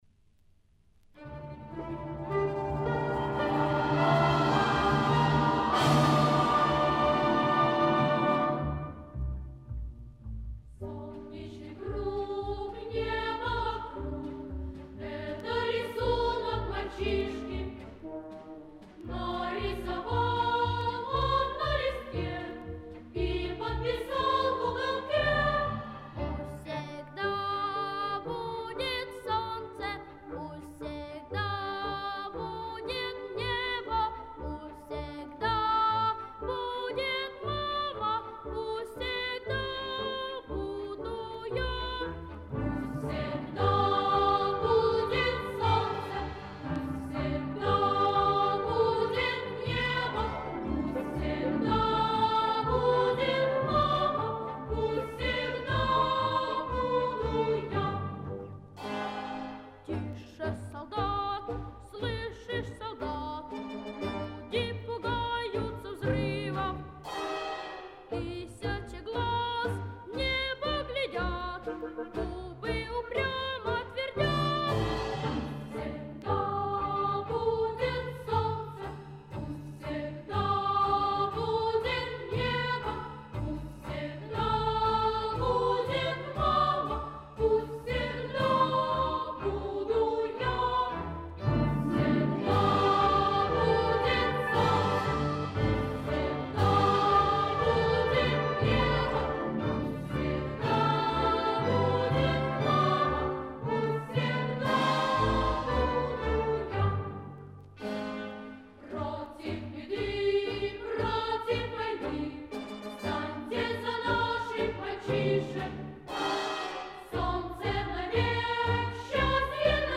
Неуемно оптимистичная песня из пионерского детства